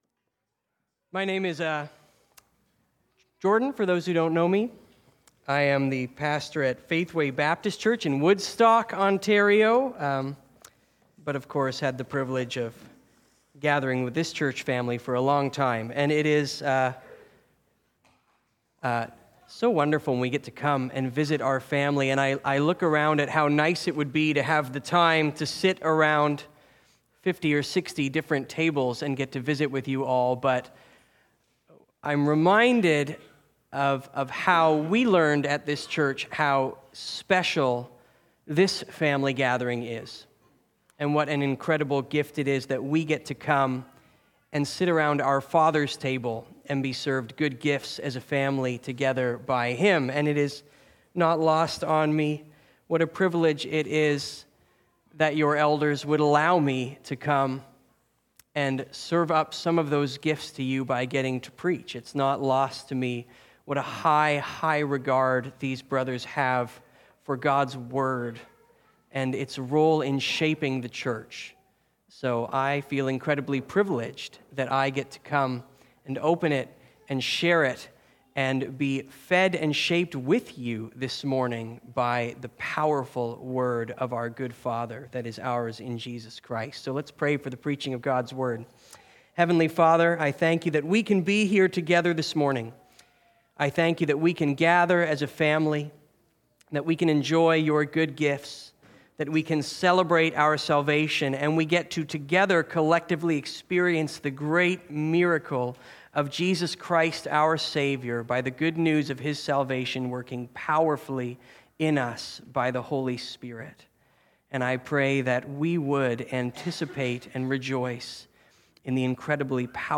Complementary New Testament Passage: John 1:1-18 Sermon Outline: The Good Creator, and His good creation.